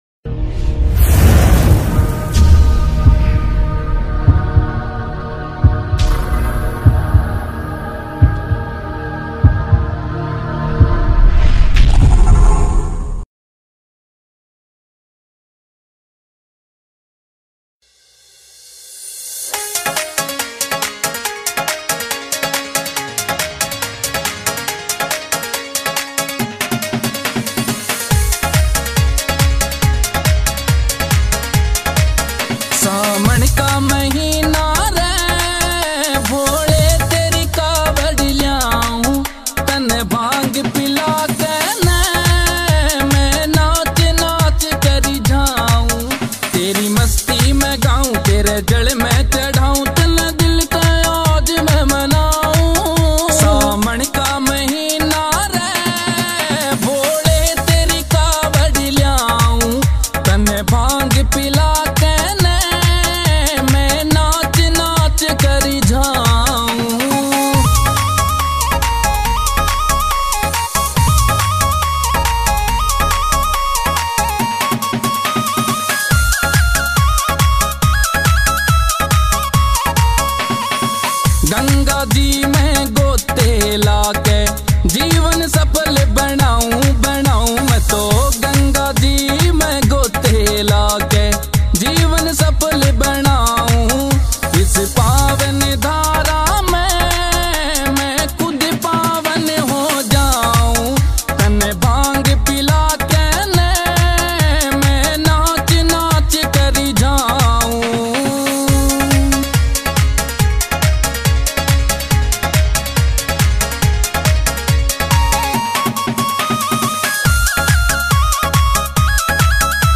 [ Bhakti Songs ]